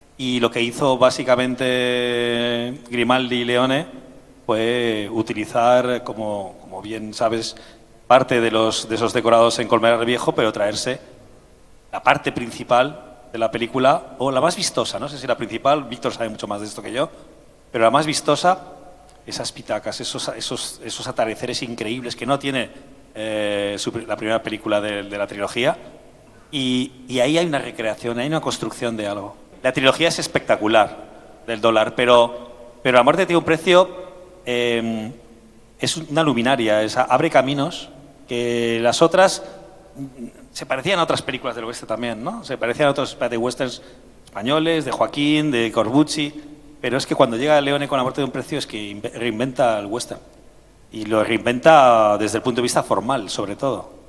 La mesa redonda
Los cuatro han ofrecido al público asistente y a los espectadores del ‘streaming’ interesantes reflexiones sobre la película así como sobre su influencia tanto para los propios implicados, como para Almería y, por qué no, también para la historia del cine.